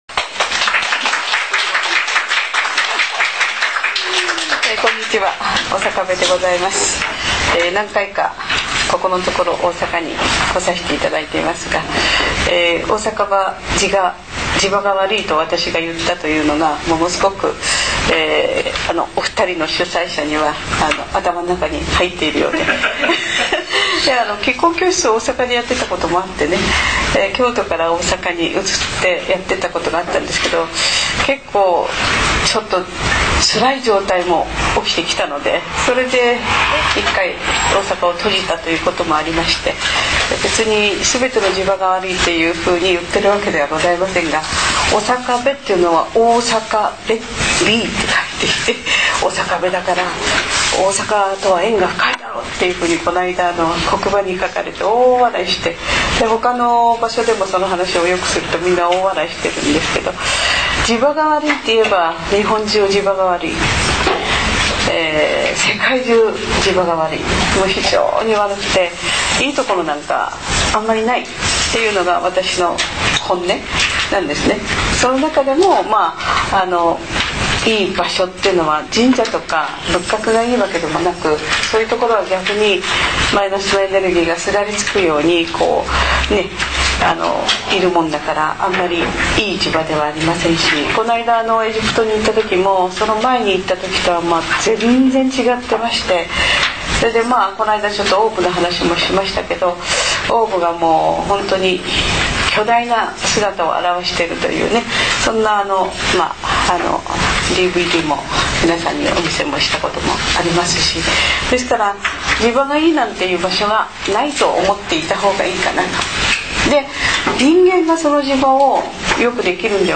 ２００８年１１月１５日　大阪市立阿倍野市民学習センターにて収録
音声の一部にノイズがございます。